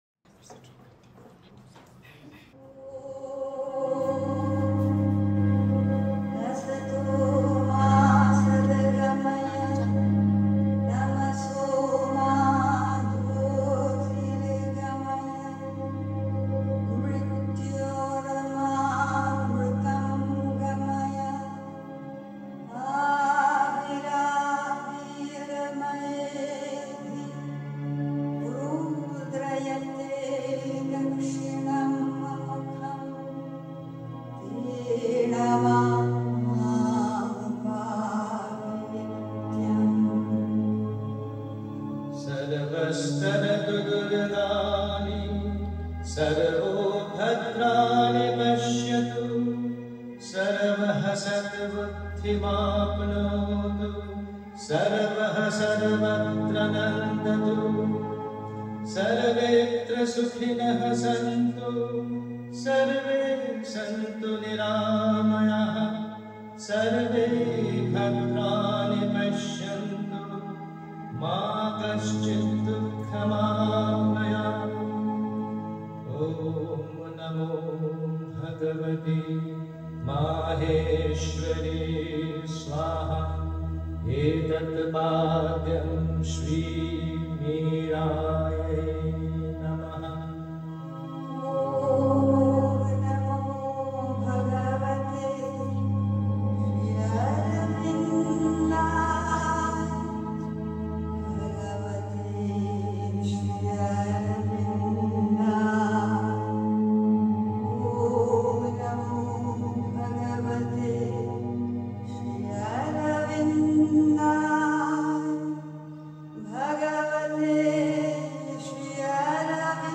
On-Death-and-True-Love-Talk-at-Aurodhan-Pondicherry.mp3